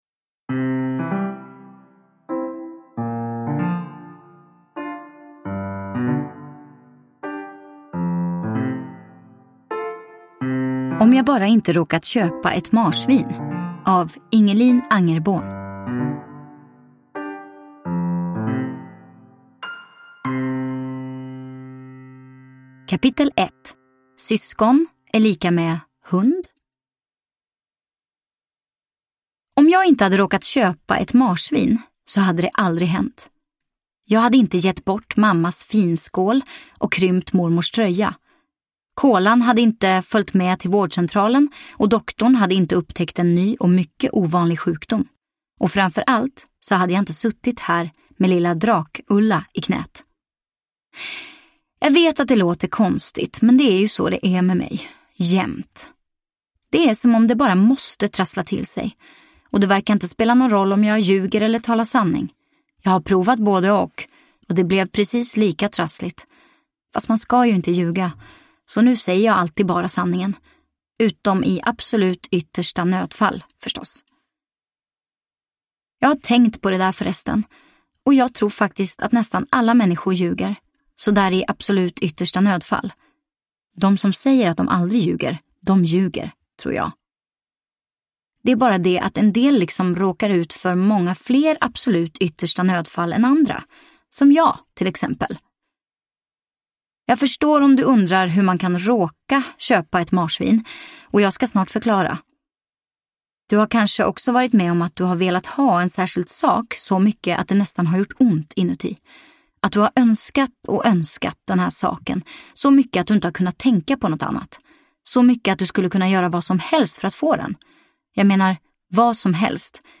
Om jag bara inte råkat köpa ett marsvin – Ljudbok – Laddas ner